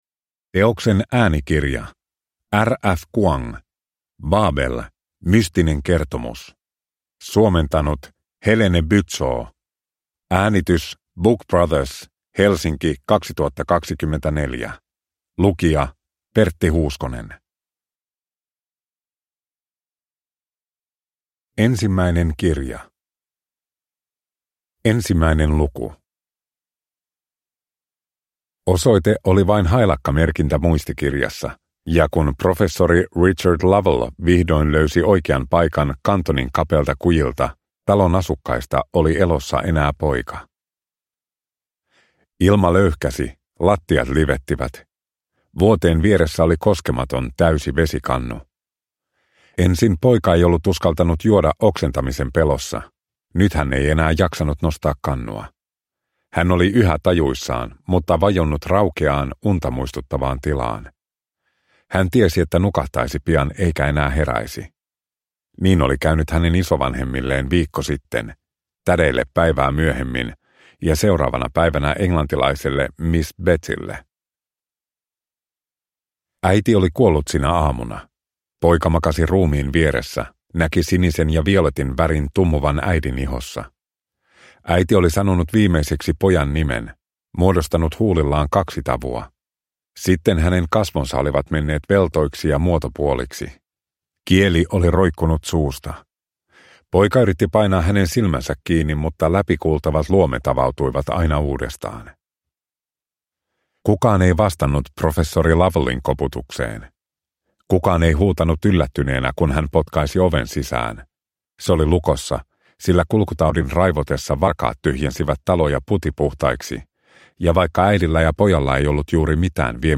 Babel (ljudbok) av R. F. Kuang